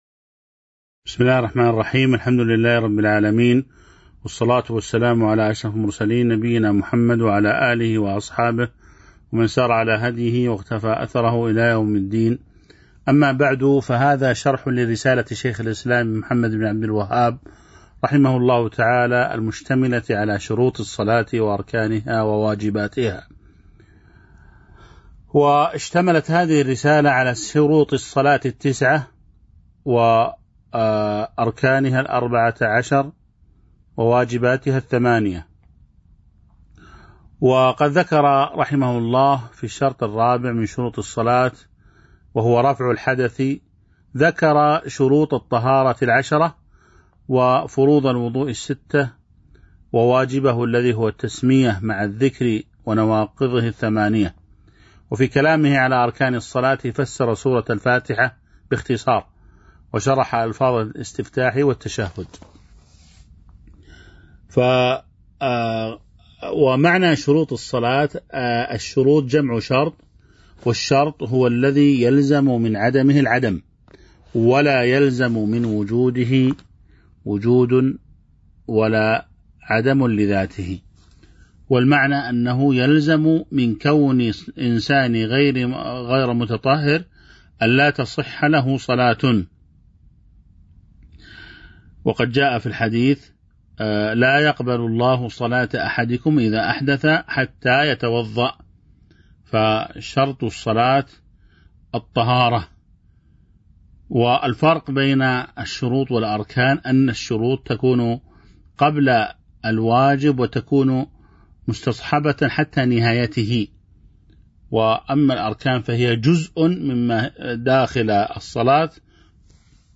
تاريخ النشر ٢٨ شوال ١٤٤٢ هـ المكان: المسجد النبوي الشيخ